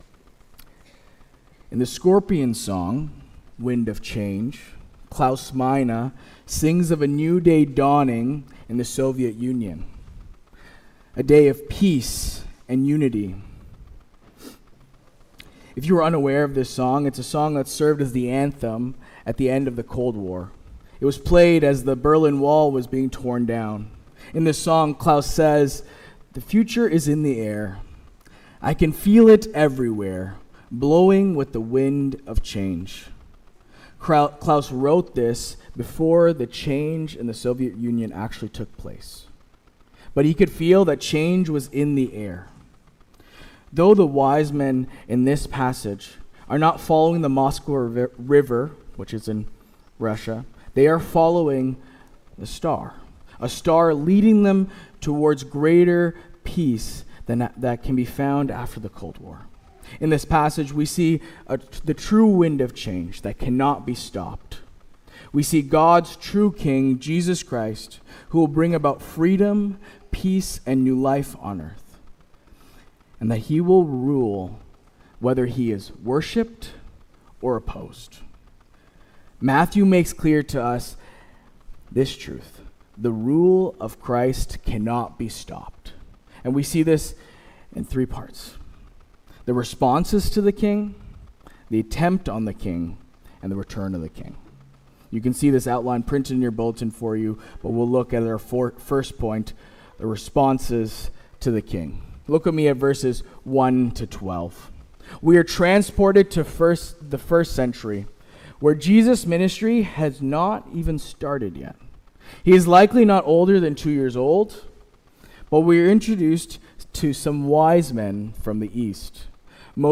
Sermon “Born to Reign”